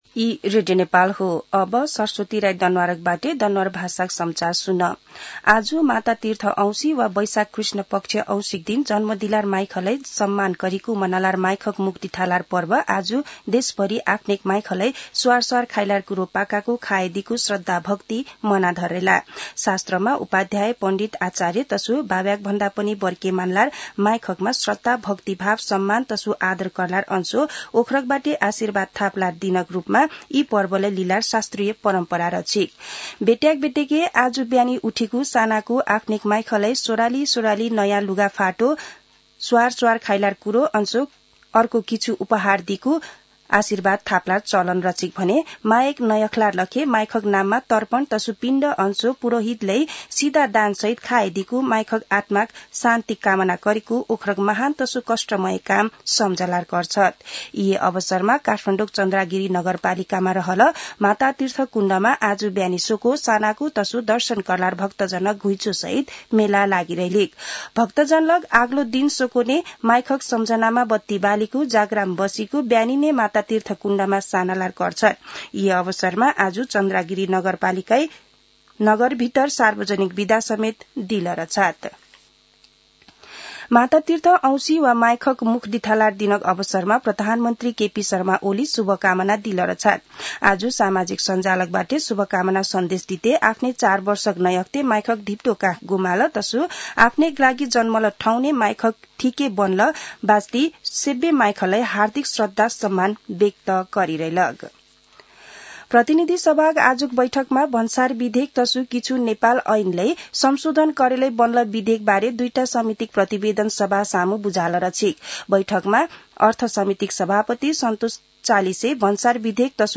दनुवार भाषामा समाचार : १४ वैशाख , २०८२
danuwar-news-2.mp3